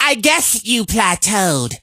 carl_kill_vo_05.ogg